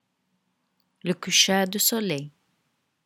The listening will help you with the pronunciations.